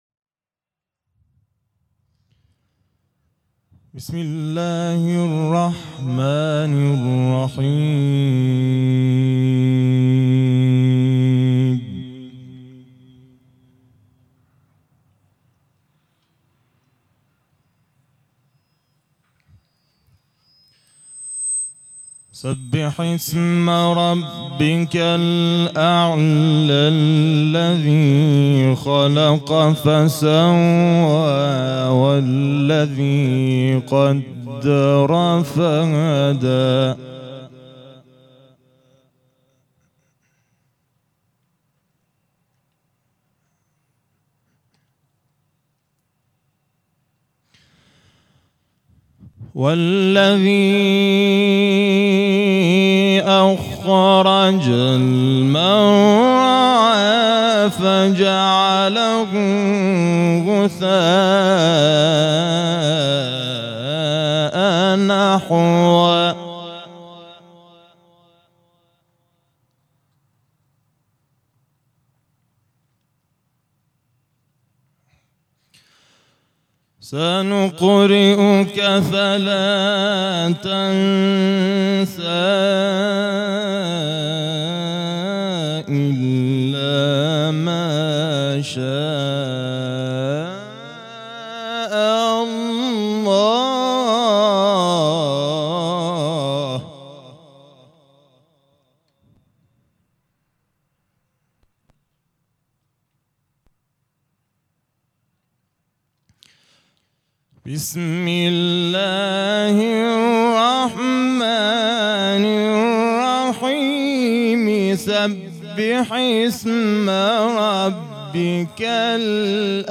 در امامزاده سید جعفرمحمد(ع) یزد قرائت شده